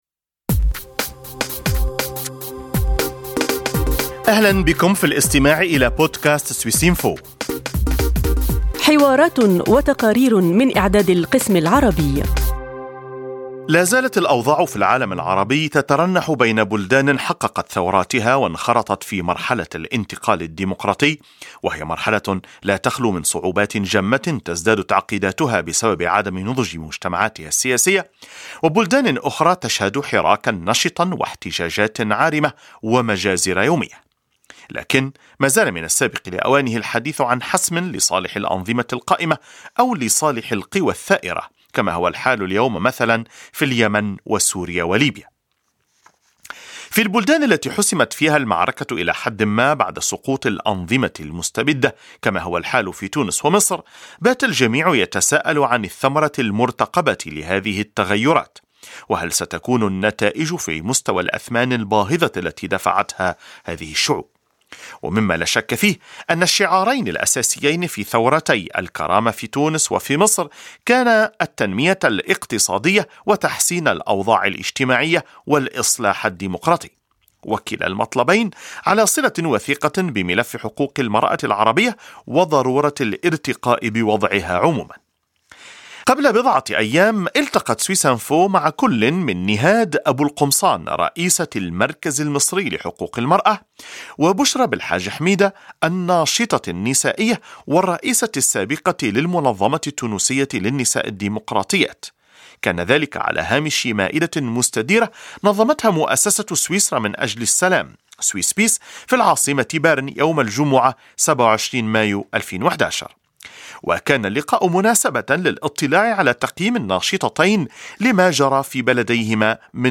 كانتا ضيفتين على ندوة خصصت لمناقشة المسألة يوم 27 مايو 2011 في العاصمة برن.